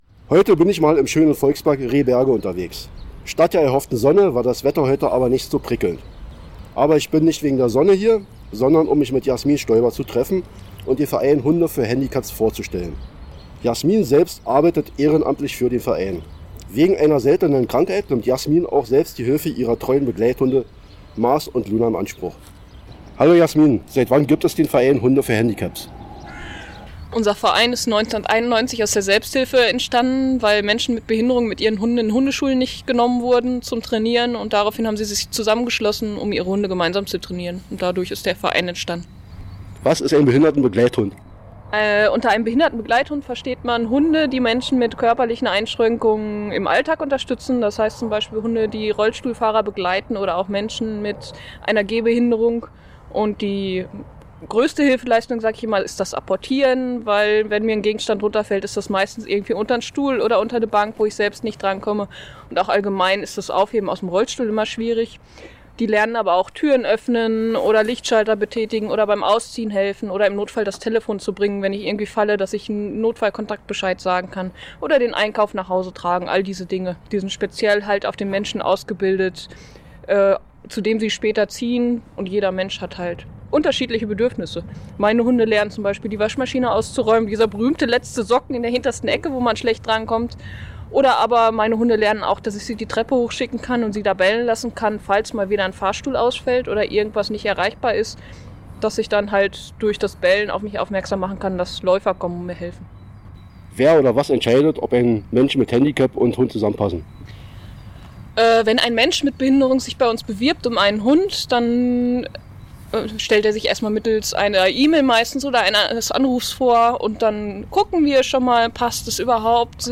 bei mässigem Wetter durch den Volkspark Rehberge